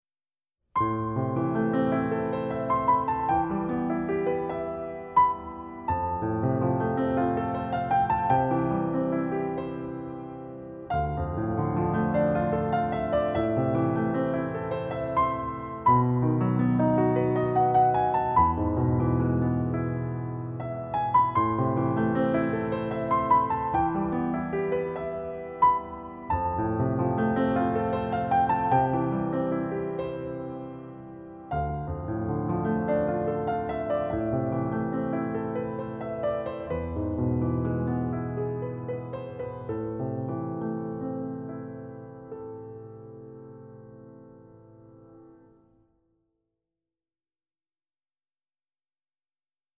Gattung: Schule für Klavier